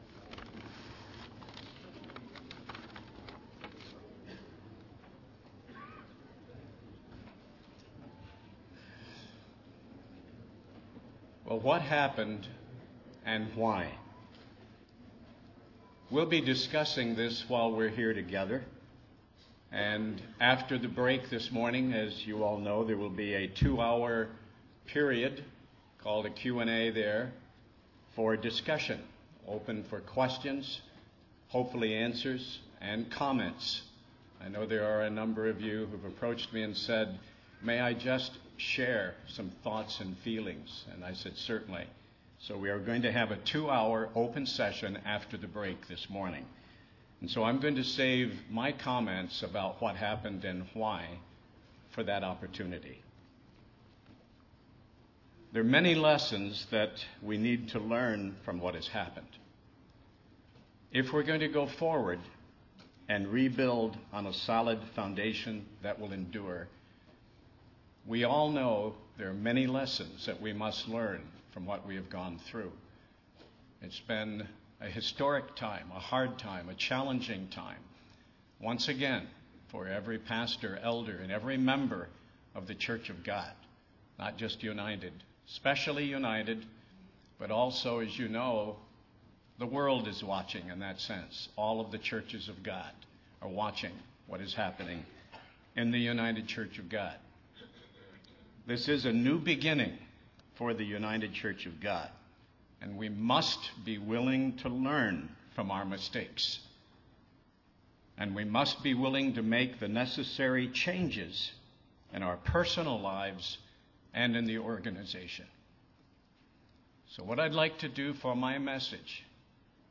Given in Hickory, NC